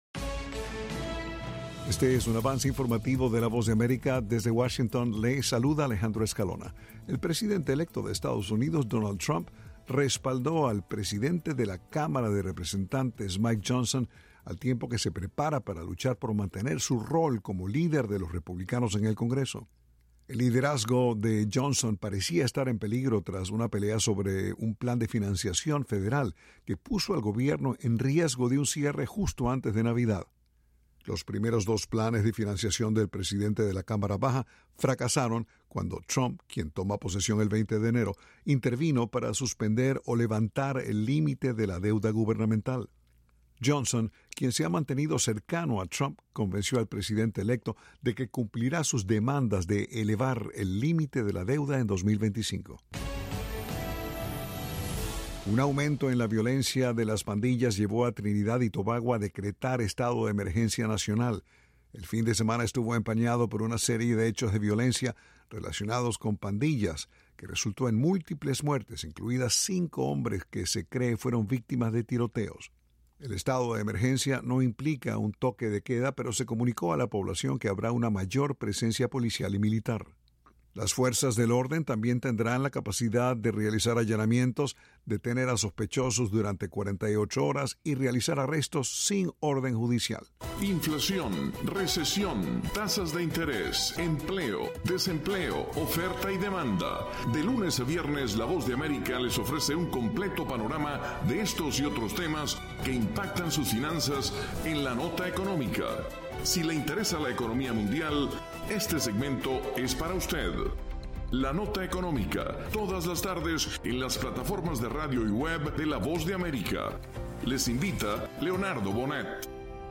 Avance Informativo
El siguiente es un avance informativo de la Voz de América.